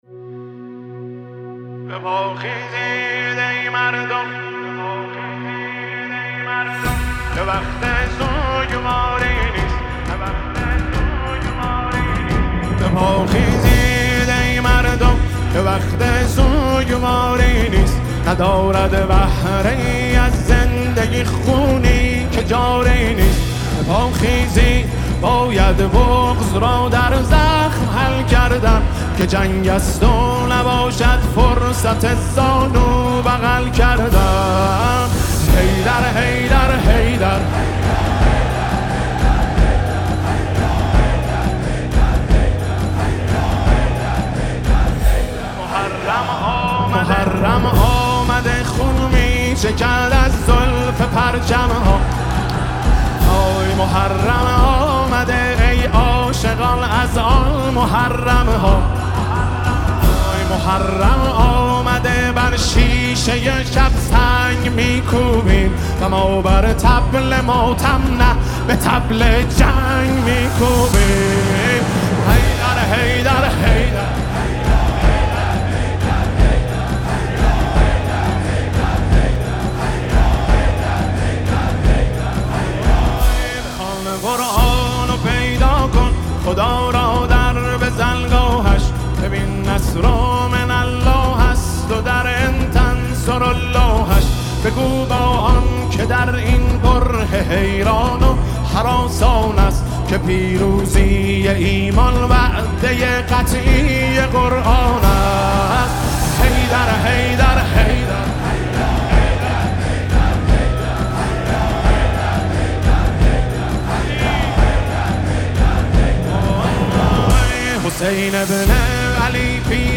حماسی خوانی مداحان برای ایران/ "اینجا ایران امام حسینه"
مهدی رسولی با چند اثر رجز می‌خواند